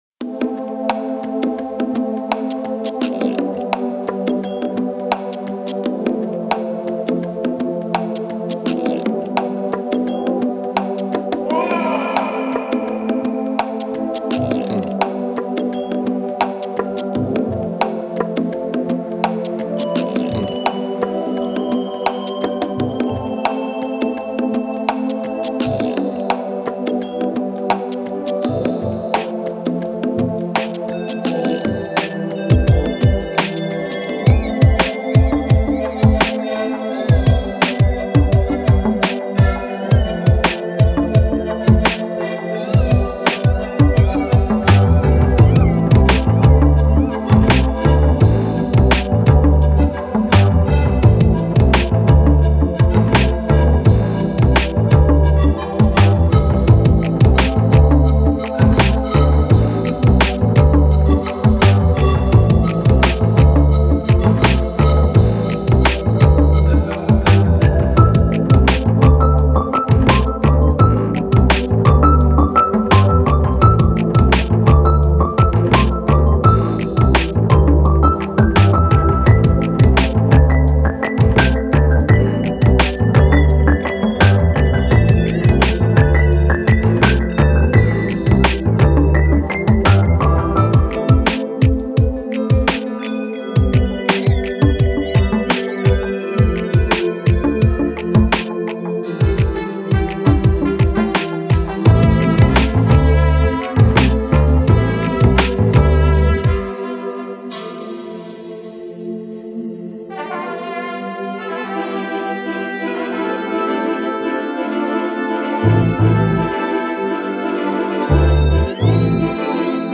Прошу критики... инструментал...